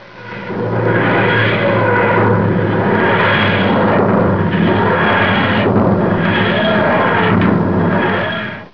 Click to hear the dematerialization.
Comic-ese for the grinding sound the TARDIS is known to make while materializing..